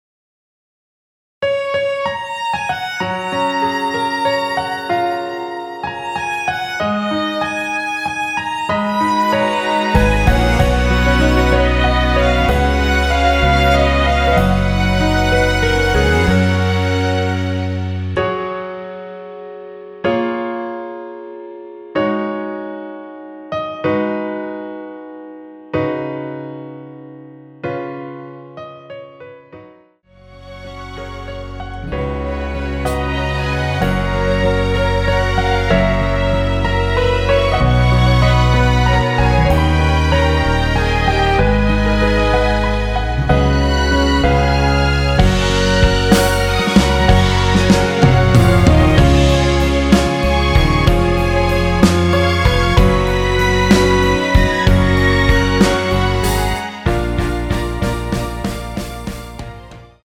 원키에서(+7)올린 1절후 후렴으로 진행되는 MR입니다.
F#
앞부분30초, 뒷부분30초씩 편집해서 올려 드리고 있습니다.
중간에 음이 끈어지고 다시 나오는 이유는